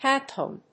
Hand+Phone.mp3